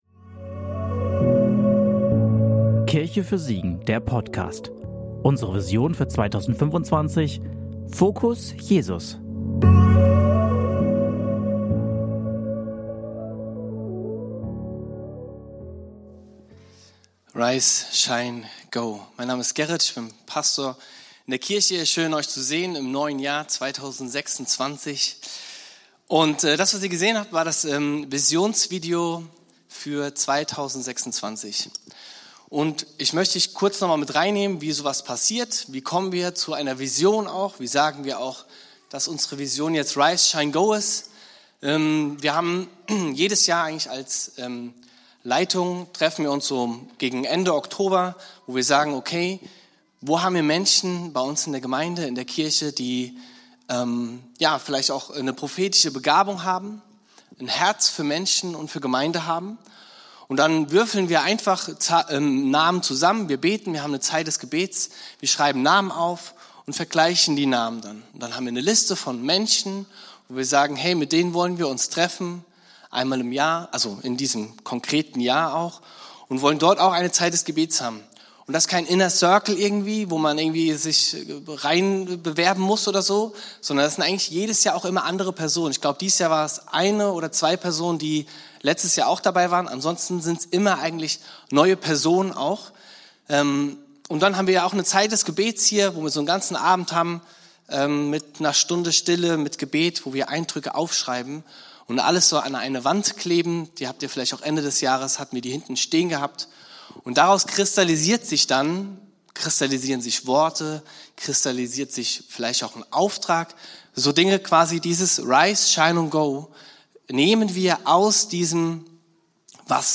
Predigt vom 18.01.2026 in der Kirche für Siegen